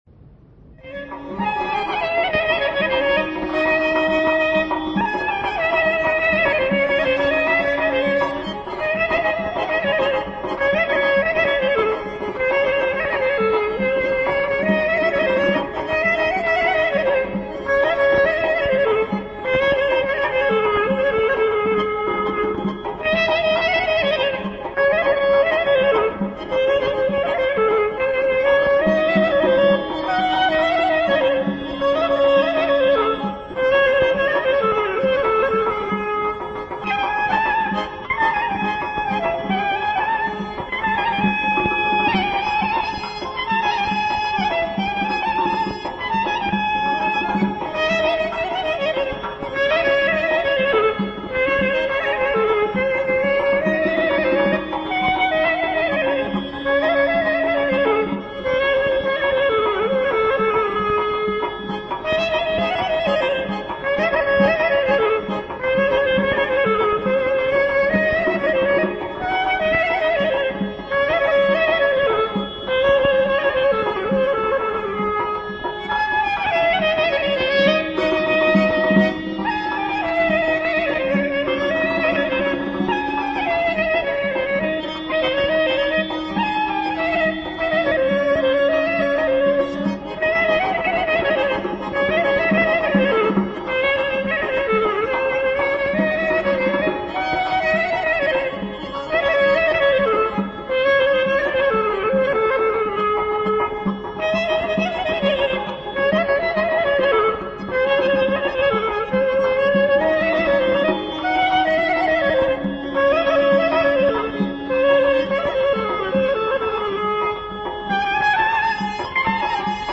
AZƏRBAYCAN XALQ RƏQS MUSİQİSİ
nağara
qarmon
klarnet
balaban.- Qramval №18036.- CD №631.